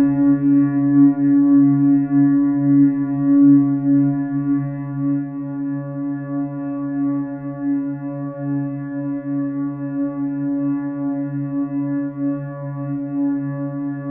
PIANOPK OC.wav